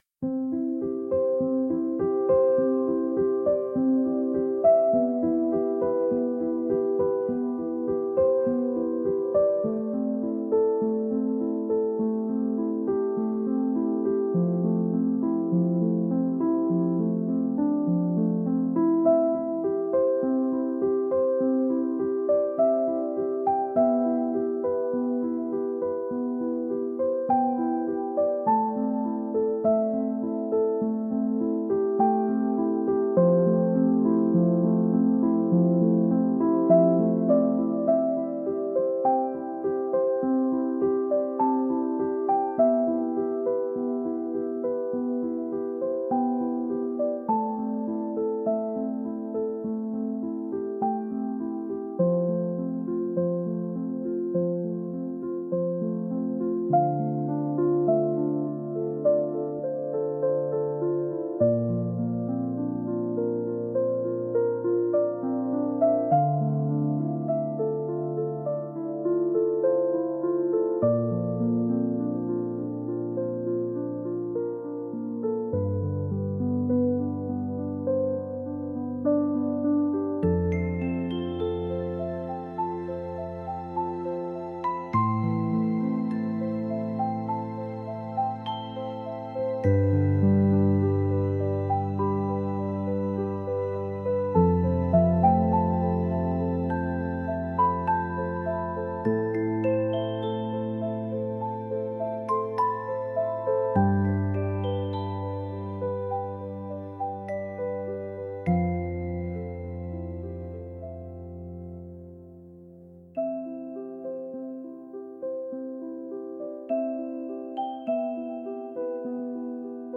幻想的